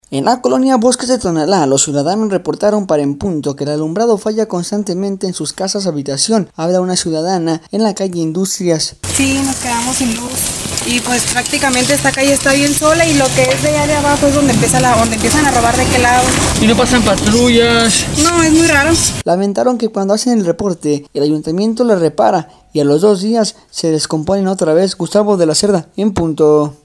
En la colonia Bosques de Tonalá, los ciudadanos reportaron para En Punto, que el alumbrado falla constantemente en sus casas habitación. Hablan una ciudadana en la calle Industrias: